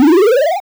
heal.wav